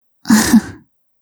语气词-轻笑.wav